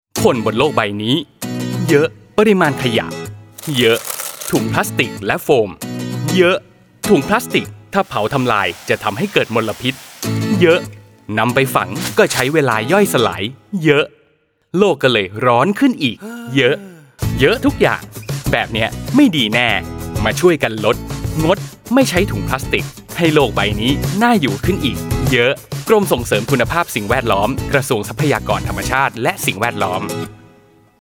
ชื่อสื่อ : สปอตวิทยุ รณรงค์งดและลดการใช้ถุงพลาสติก